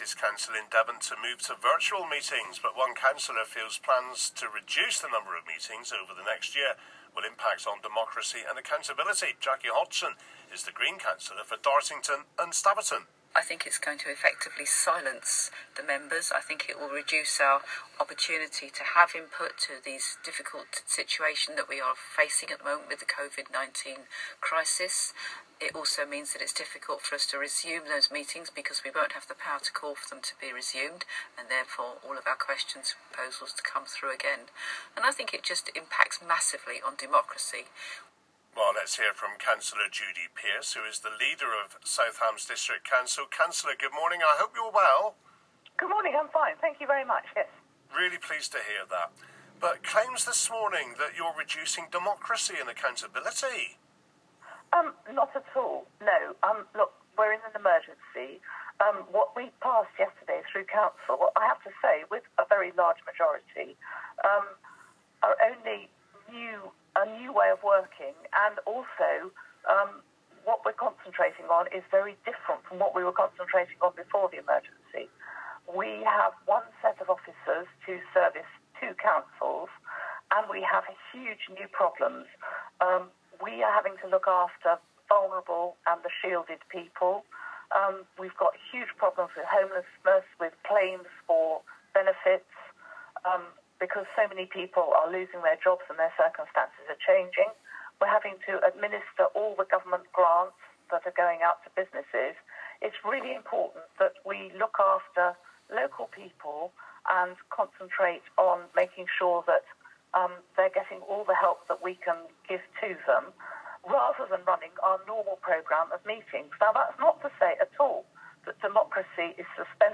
Recording of BBCRadio Devon clip 24.4.20 7.40am
bbc-devon-24.4.20-re-shdc.mp3